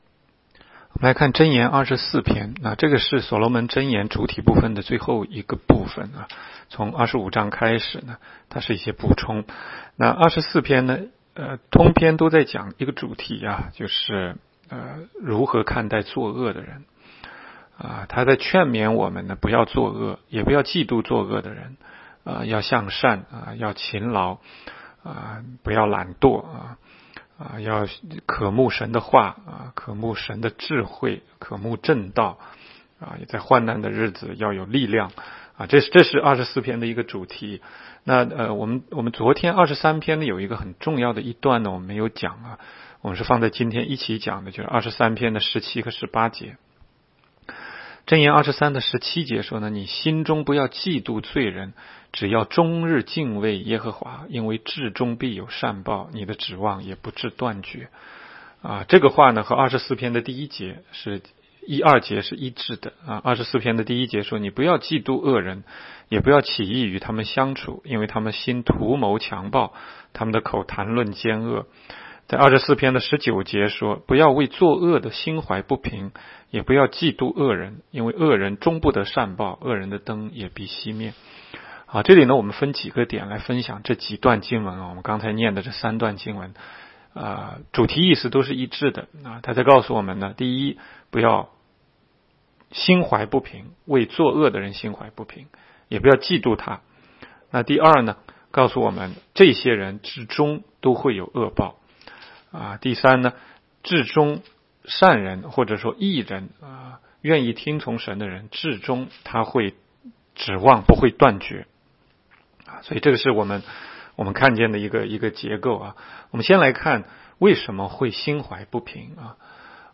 16街讲道录音 - 每日读经 -《 箴言》24章